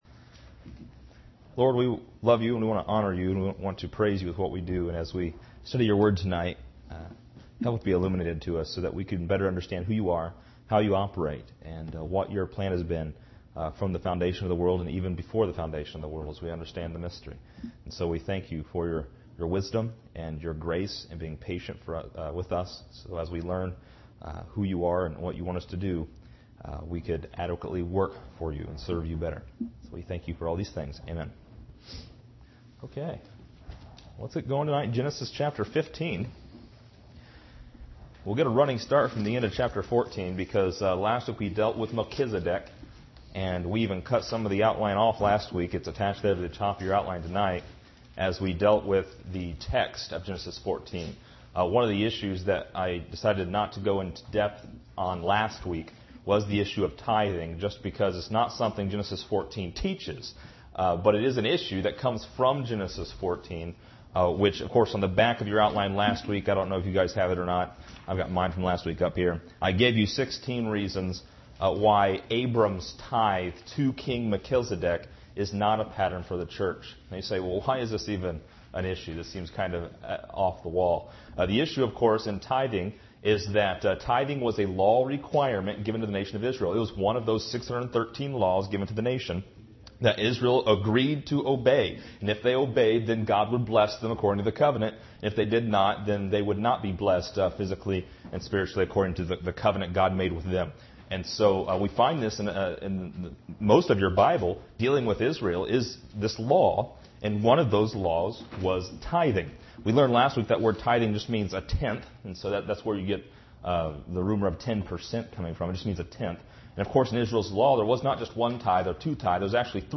This lesson is part 25 in a verse by verse study through Genesis titled: Abram Counted Righteous.